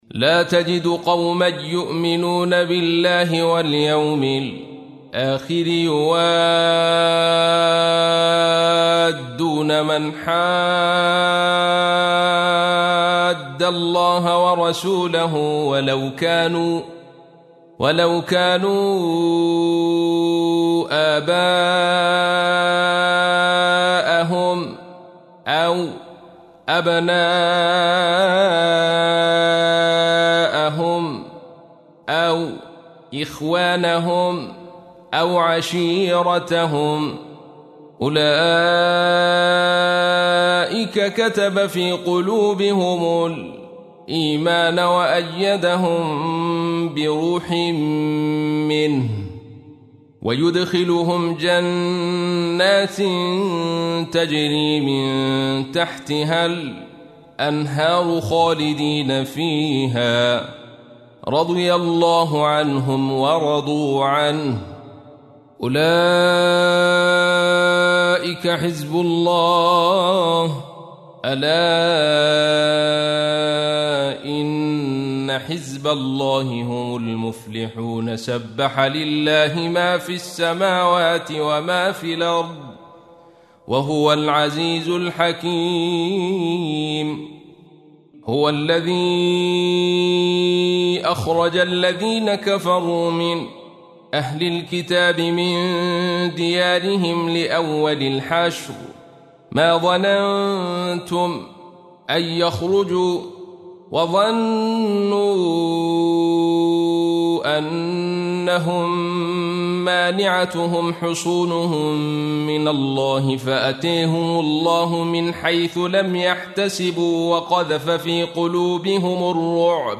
تحميل : 59. سورة الحشر / القارئ عبد الرشيد صوفي / القرآن الكريم / موقع يا حسين